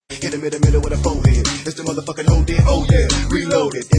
Play, download and share Hit em in the fohead original sound button!!!!
ak47-foheadmp3.mp3